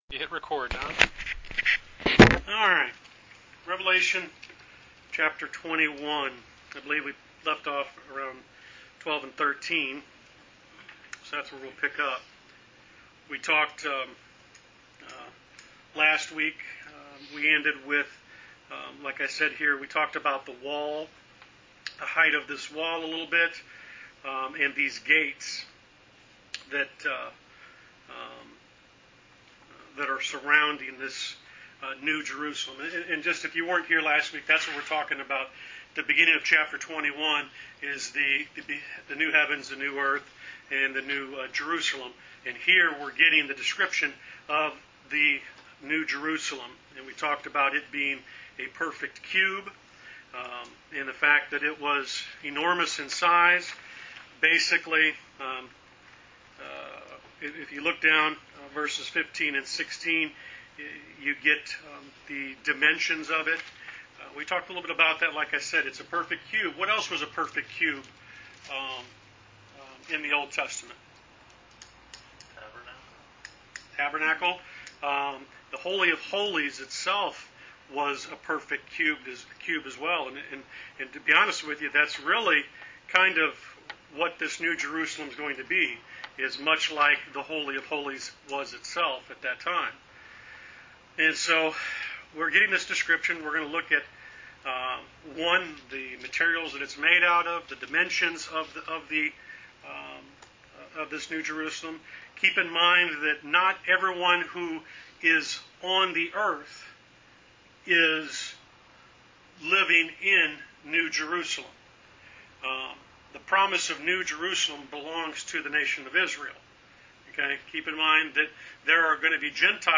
Wednesday Bible Study: Rev 21 vs 12-27